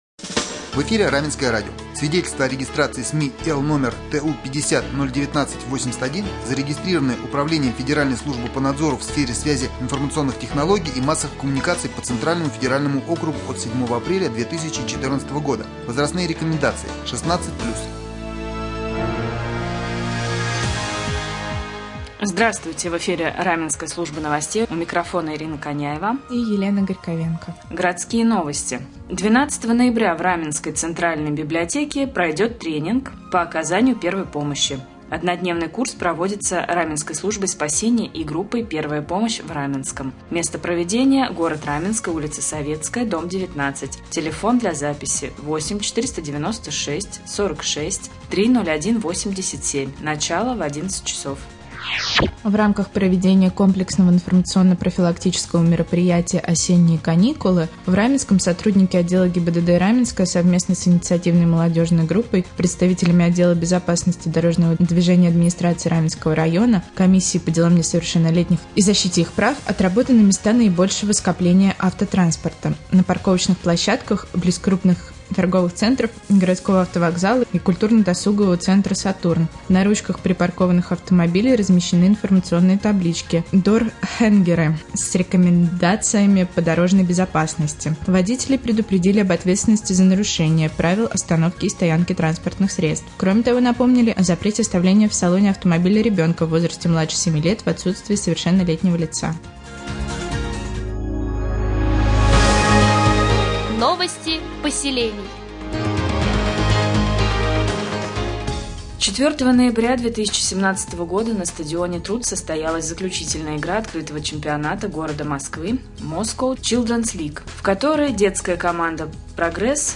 Сегодня в новостном выпуске на Раменском радио Вы узнаете, когда в Раменском пройдет тренинг по оказанию первой помощи, как прошла проверка парковочных пространств, а также последние областные новости и новости соседних районов.